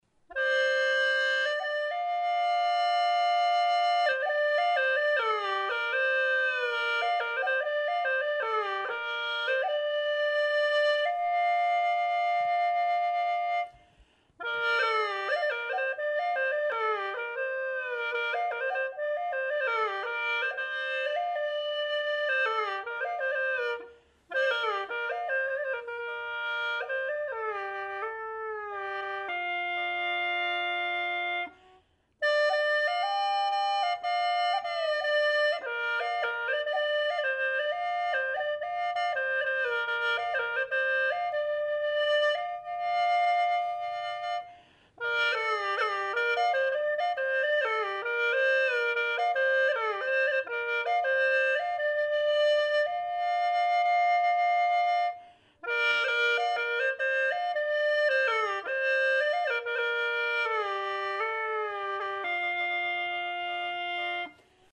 free reed pipe solo the instrument known in China as hulusi 1MB
hulusi.mp3